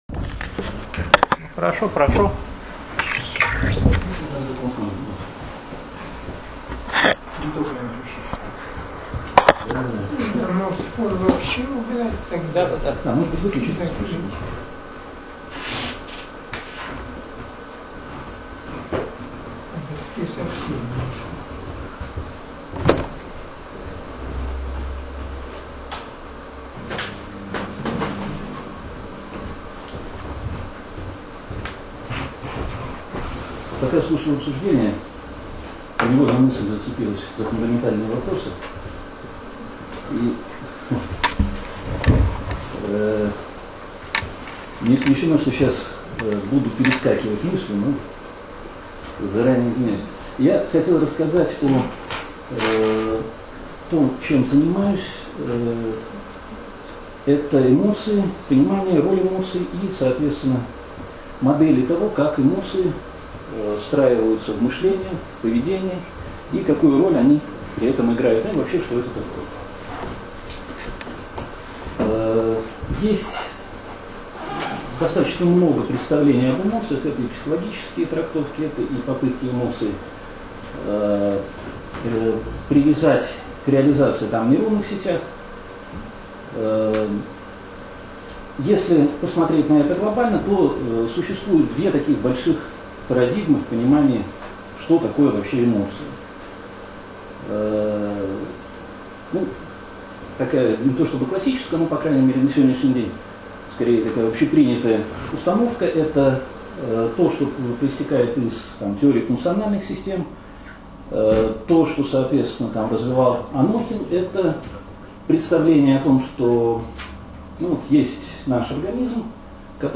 Downloads | Аудио | Семинар - AI Lab